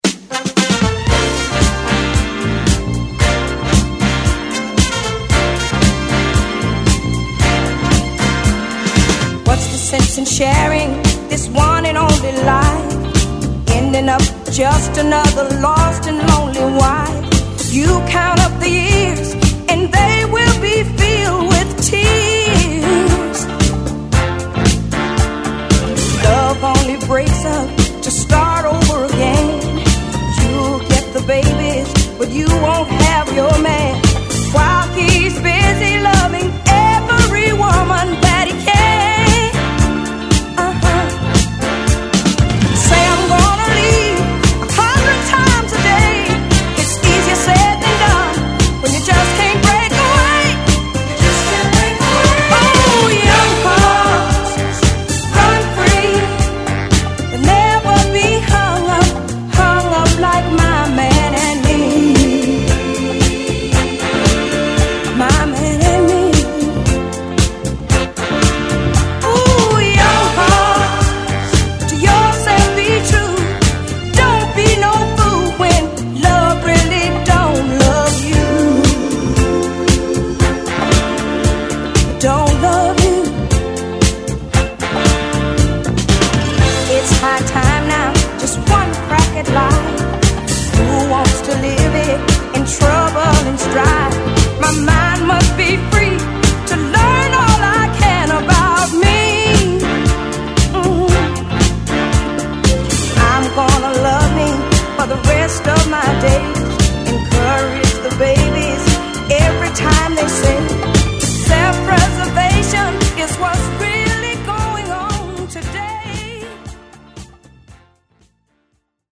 Then I sang it in one take.
The hurt in my voice is real.